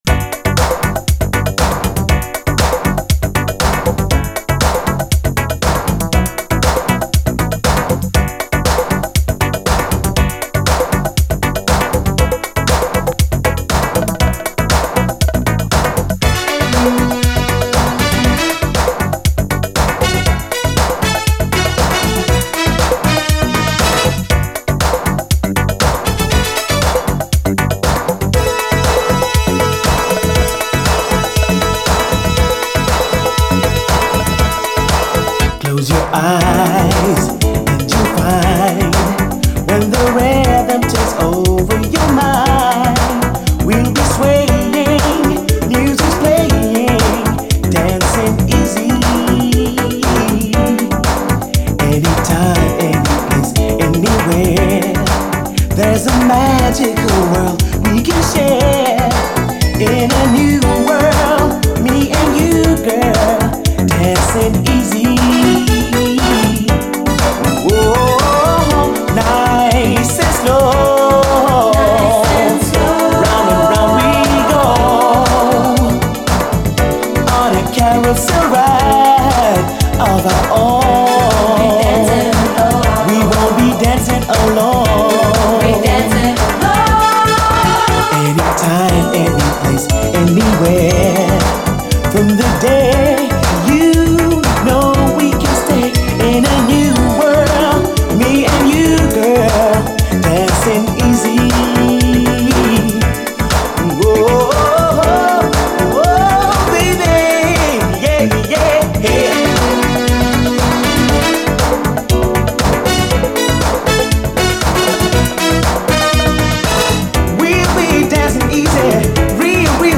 DISCO, DANCE
オランダ産の流麗80’Sシンセ・ブギー！